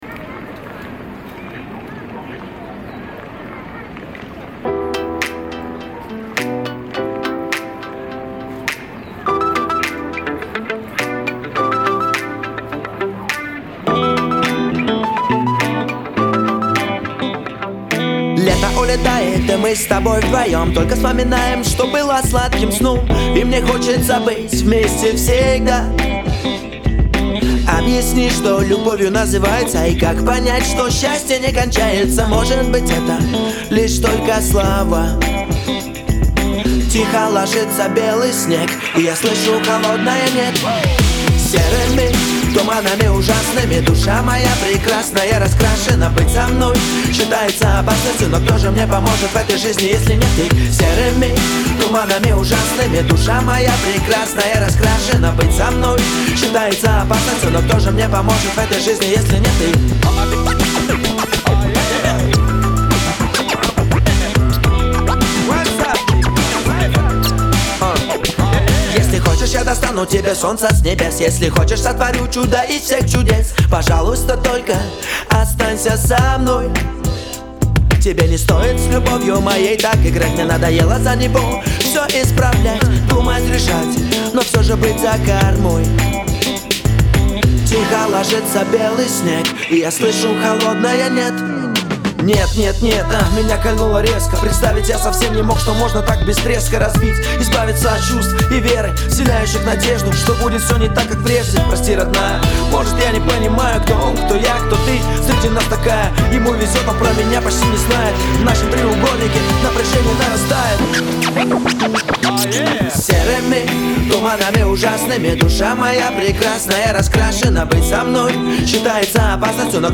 хип-хоп